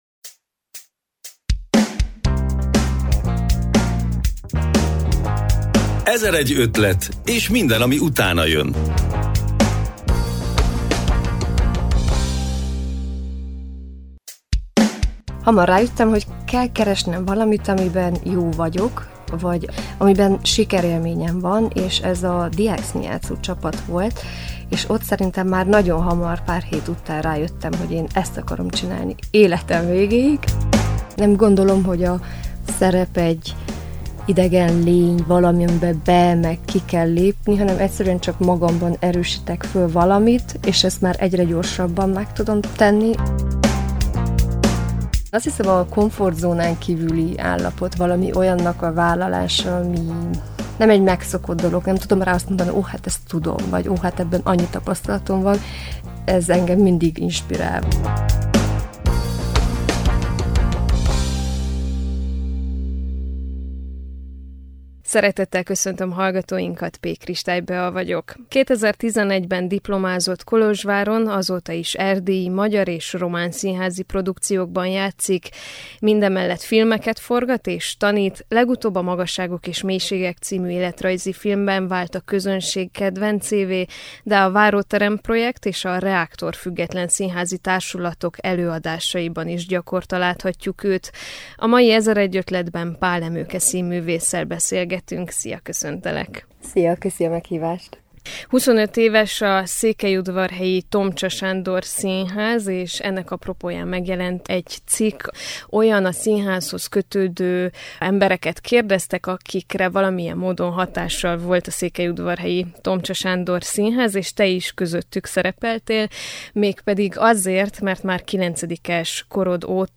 A beszélgetés a lejátszóra kattintva hallgatható meg,